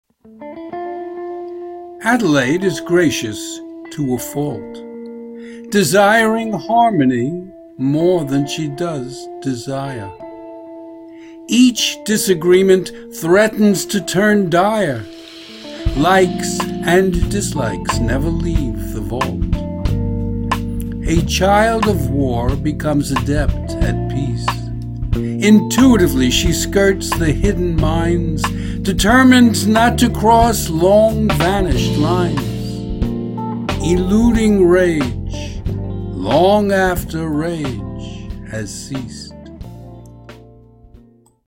Hear me read the poem as an MP3 file.